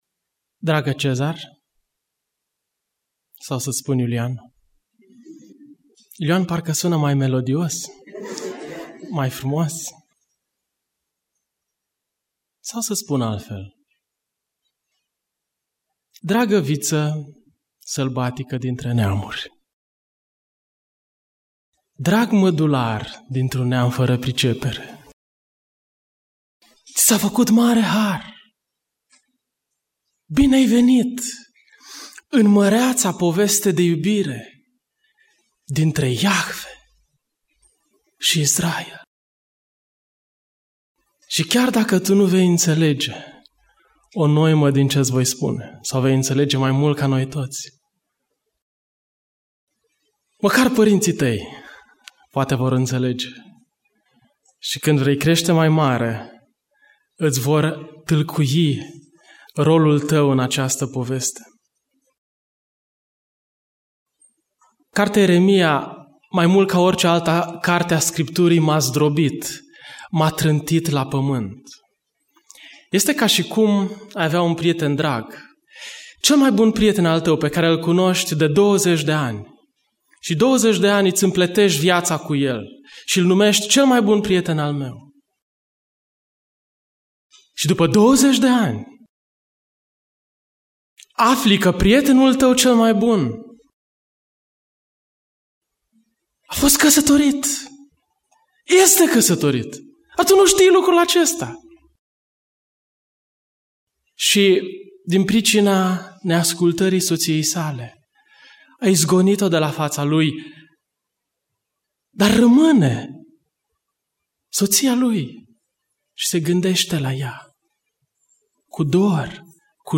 Predica Aplicatie - Ieremia 37-38